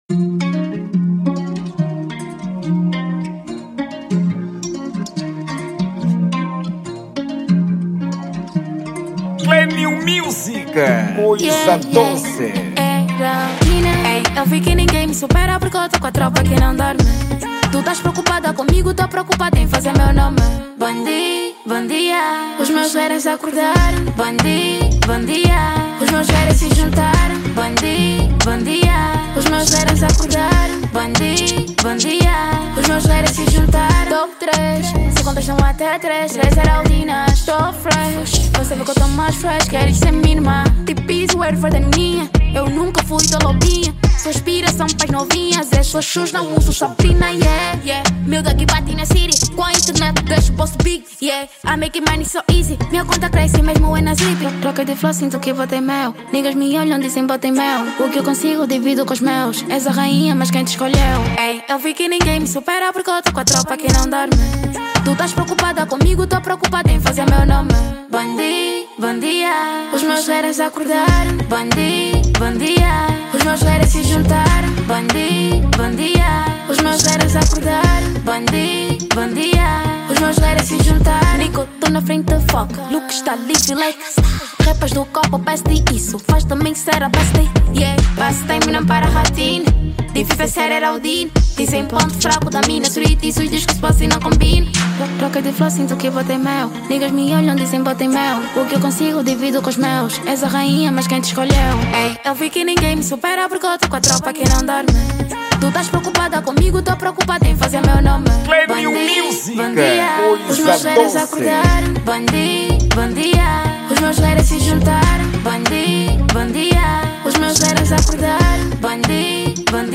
Género: Trap